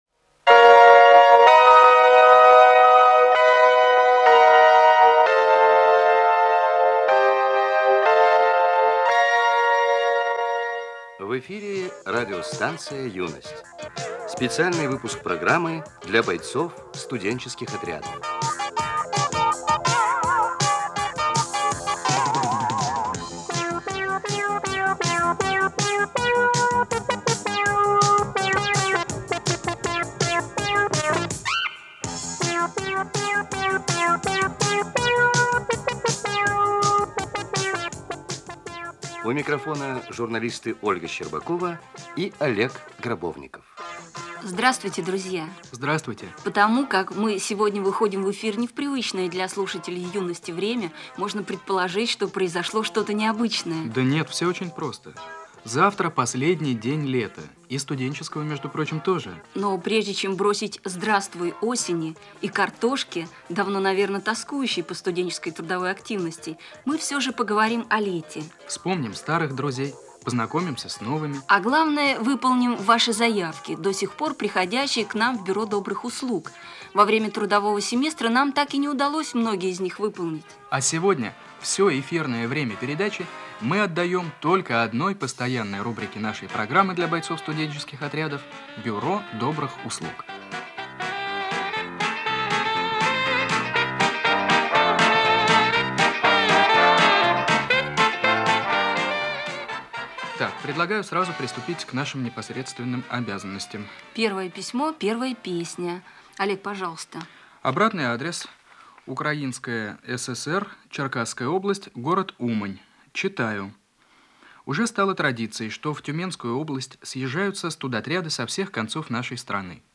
Концерт по заявкам.